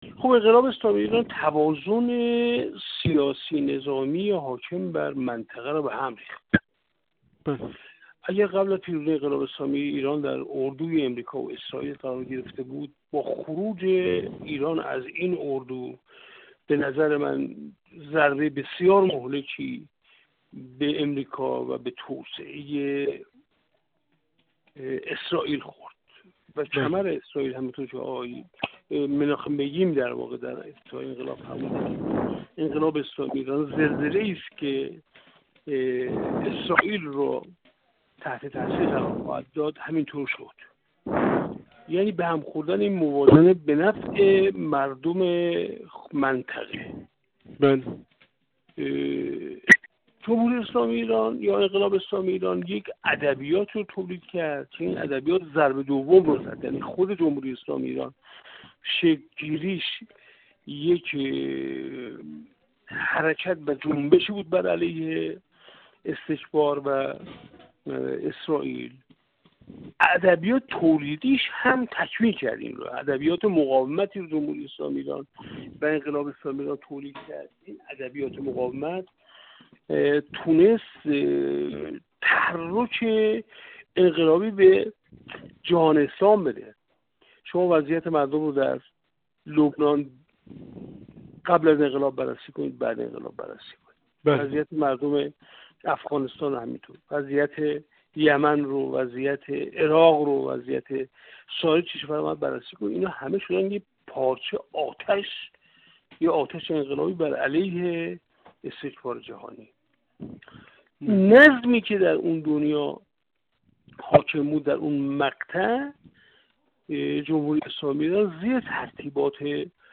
منصور حقیقت‌پور، کارشناس مسائل سیاسی و نایب رئیس کمیسیون امنیت ملی و سیاست خارجی در مجلس دهم، در گفت‌وگو با ایکنا درباره مهمترین تغییر و تحولات در نظم جهانی و منطقه‌ای با وقوع انقلاب اسلامی گفت: انقلاب اسلامی ایران در وهله اول توازن سیاسی ـ نظامی حاکم بر منطقه را به هم زد.